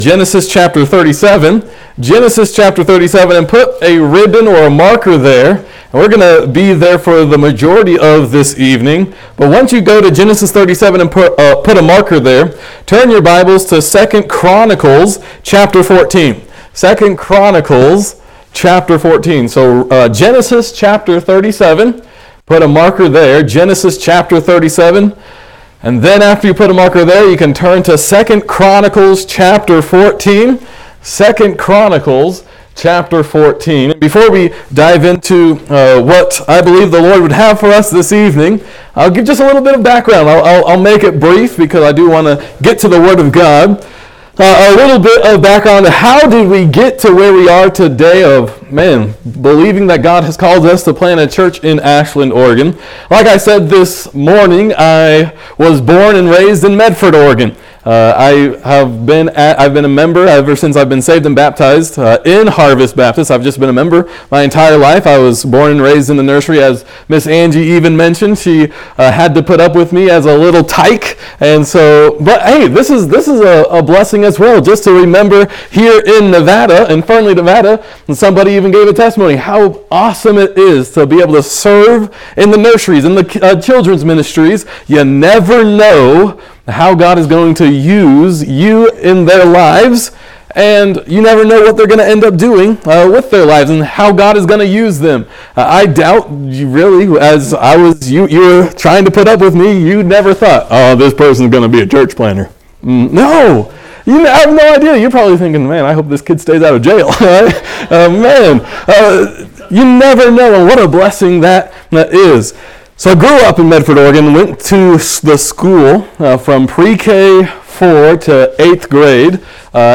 A message from the series "General Series."